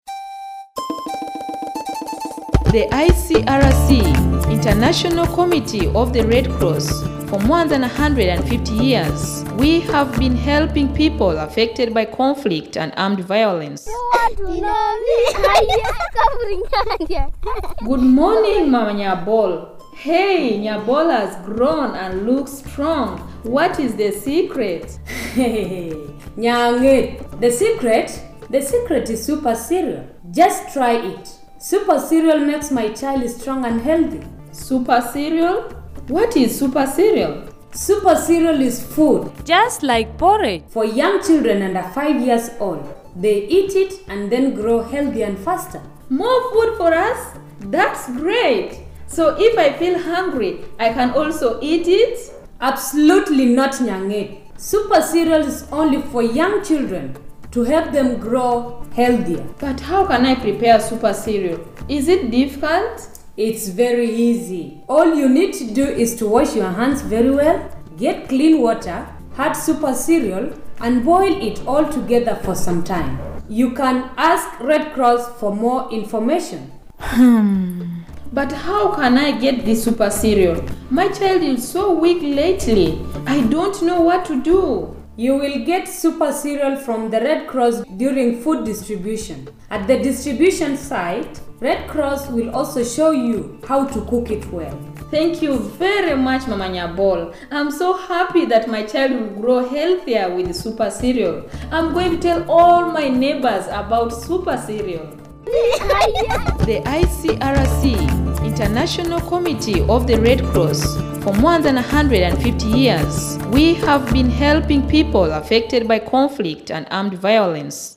M06Nutrition_FinalSkitEngishdramaonSuperCereal.mp3